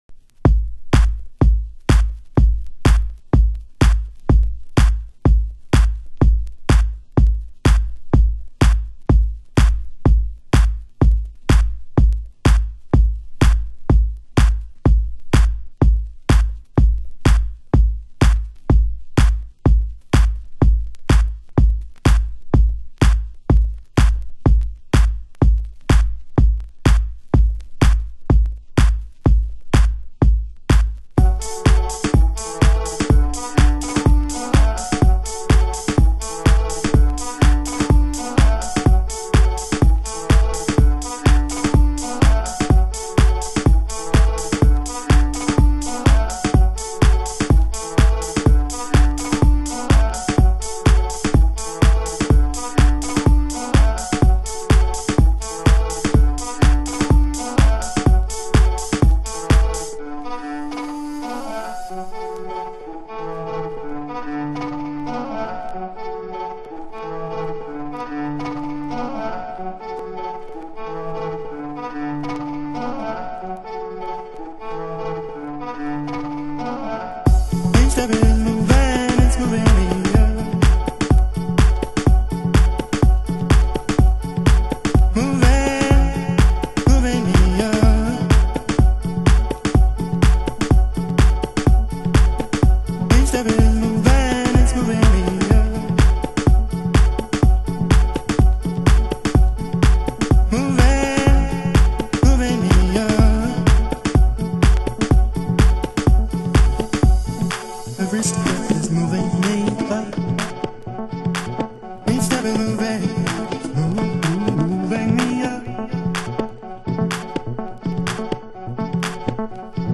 盤質：小傷、軽いスレ傷有/少しチリパチノイズ有